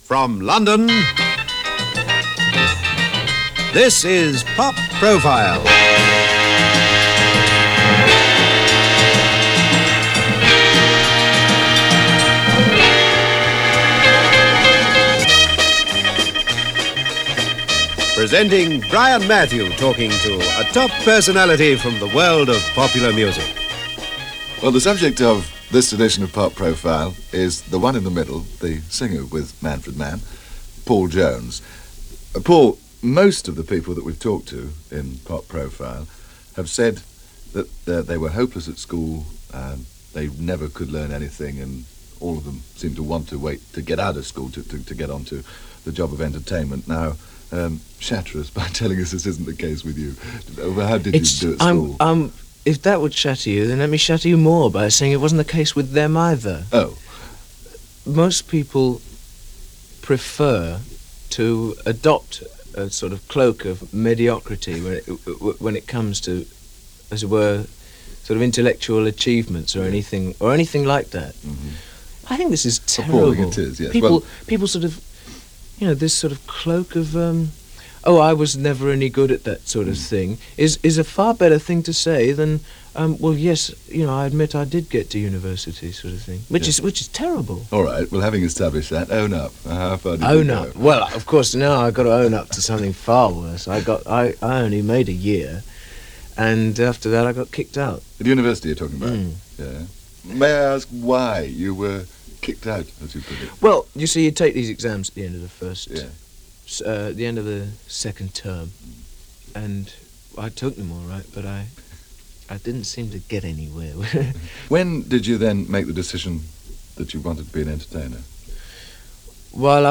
BBC Pop Profile – Brian Mathew talks with Paul Jones – (circa 1965) – BBC Transcription Service –
Here is an interview Jones did with Brian Mathew for the BBC Transcription service program Pop Profiles around 1965 (no specific dates).